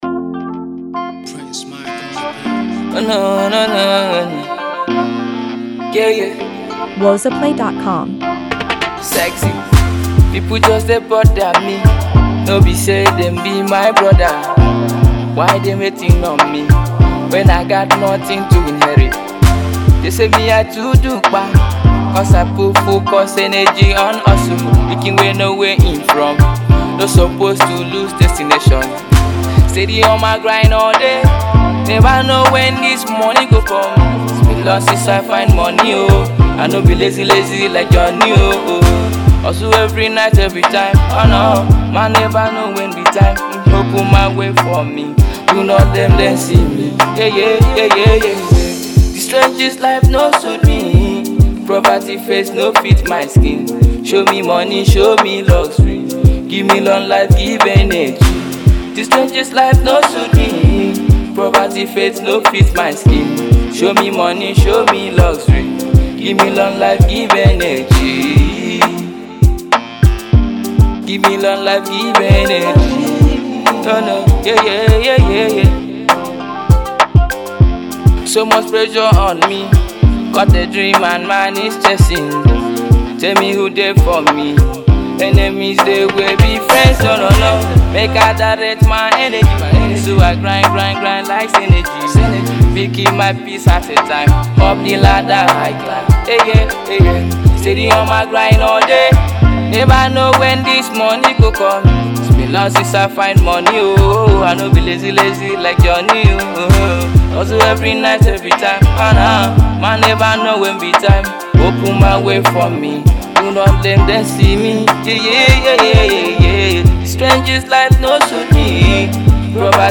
raw, emotive delivery